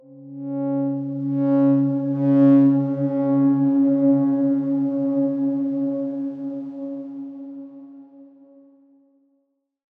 X_Darkswarm-C#3-mf 2.wav